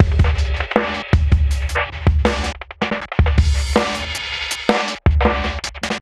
💥💥💥 Аудио записи 80BPM - слушать звуки онлайн и скачать бесплатно ✔в хорошем качестве готовый аудио файл (sample, loop) 80BPM для создания музыки.
Sound_11394_ChopperBeatB.ogg